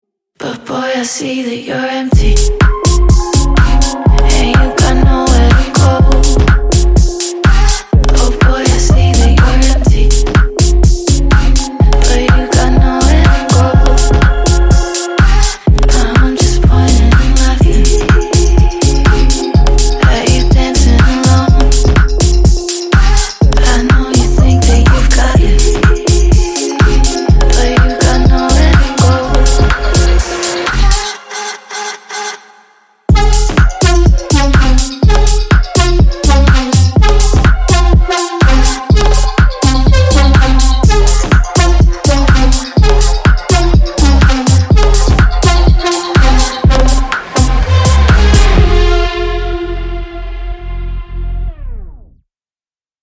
Танцевальные рингтоны , Рингтоны техно
Спокойные рингтоны
Нежные рингтоны
electronic